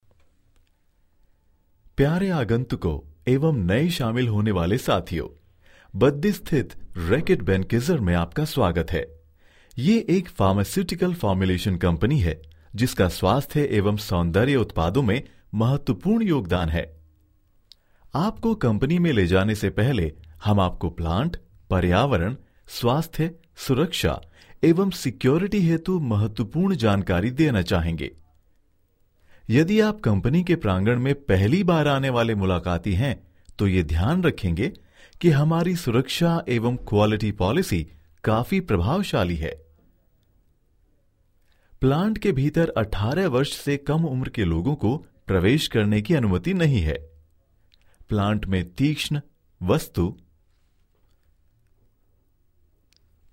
Professional non-union Hindi voice actor
Sprechprobe: Industrie (Muttersprache):